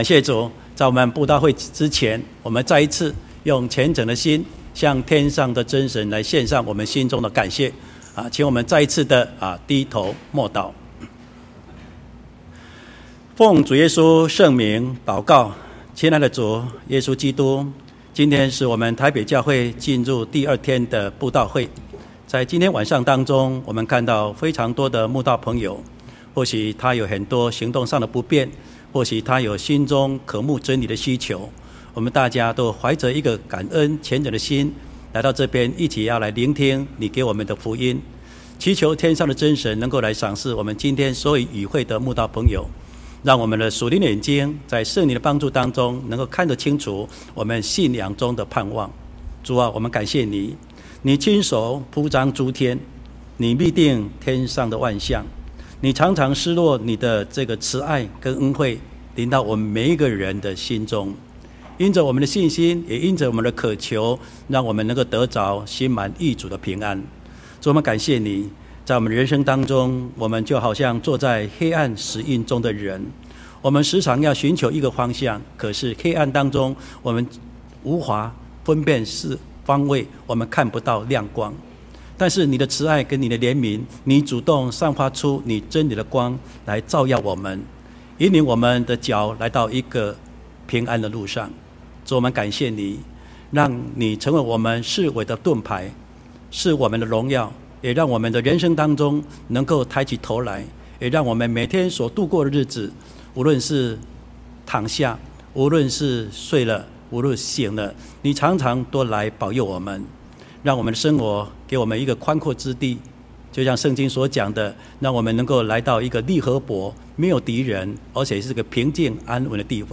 秋季靈恩佈道會：歸向光明-講道錄音